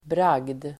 Uttal: [brag:d]